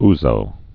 (zō)